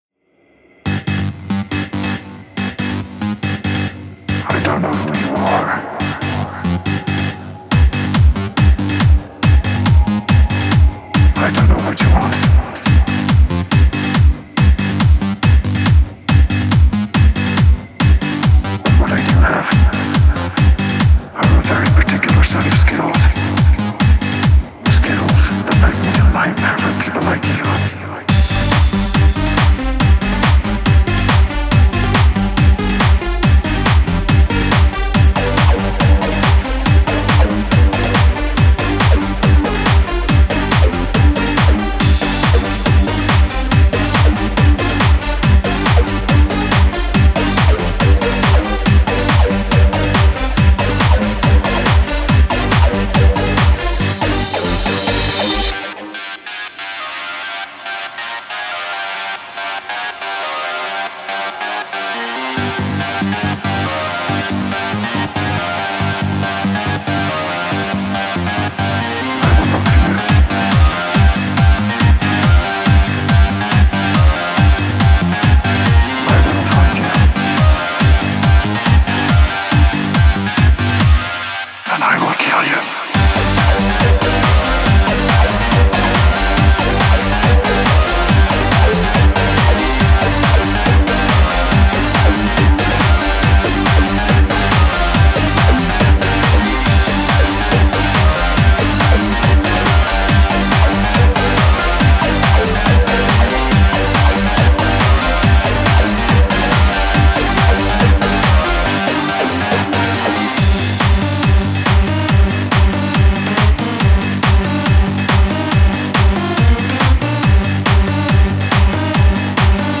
Industrial, Dark Electro
exploring the paths of clubbing goth music